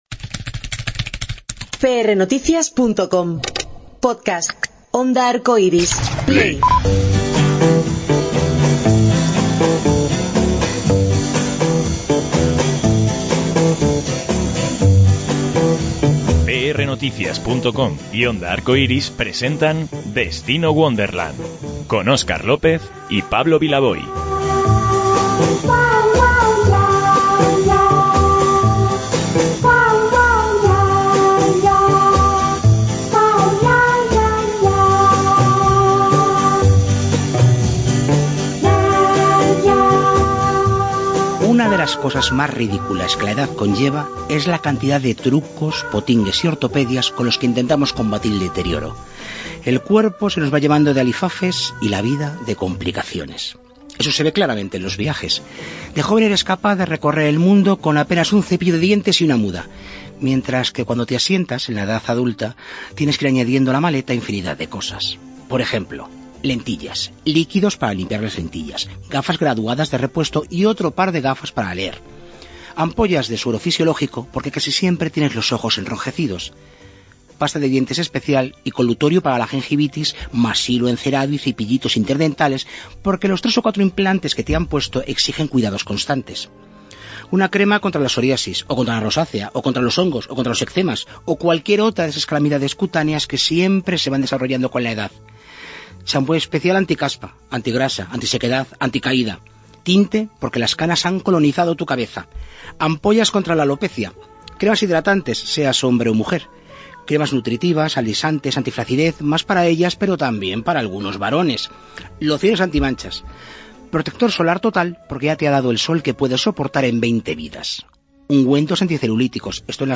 Charo Reina nos recibió en su camerino del Teatro Arlequín de Madrid donde triunfa junto a Marta Valverde, Edith Salazar y Belinda Washington con Menopause, el musical de Broadway , tal ha sido la recepción tributada por el público que el espectáculo se mantendrá en cartel hasta junio de 2017, “por el momento”, apostilla la artista entre sonoras carcajadas, pletórica con una función que le permite cantar temas muy diferentes a los que el público le demanda (antológico es su “Only You”, por su interpretación y por el objeto al que va dedicado).